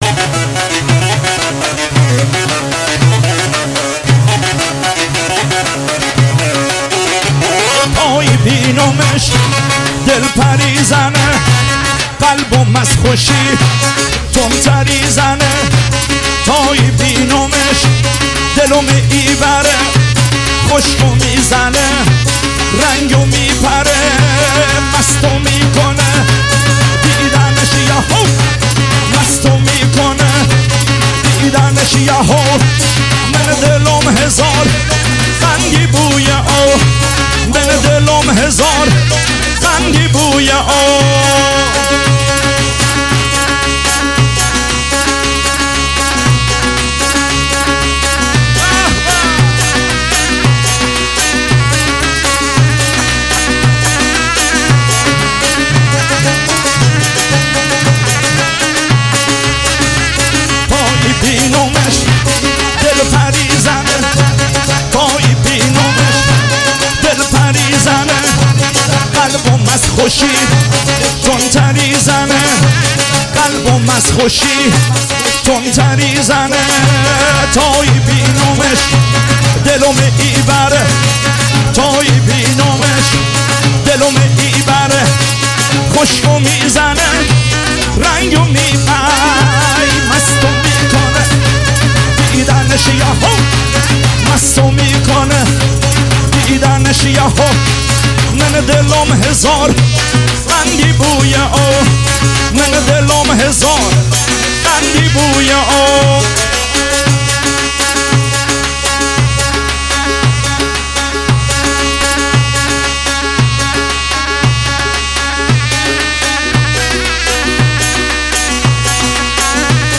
محلی لری عروسی